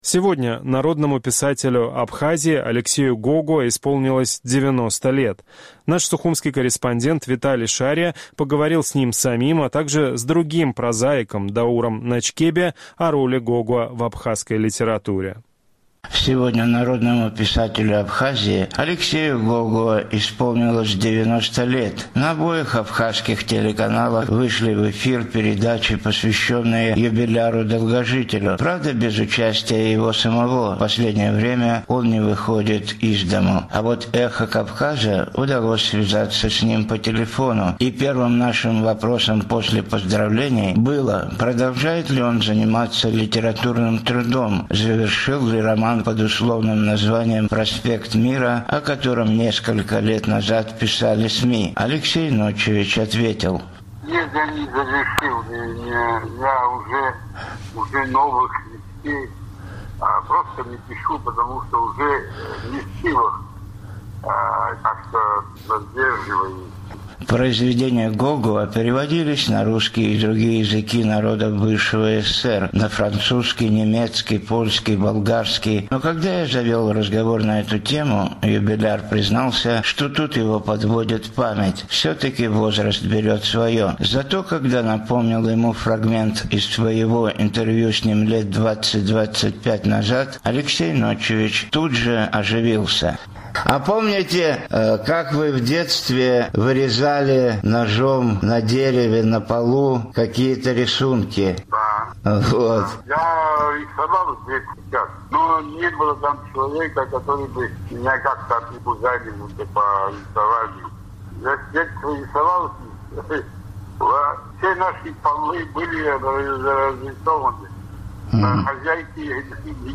А вот «Эху Кавказа» удалось связаться с ним по телефону.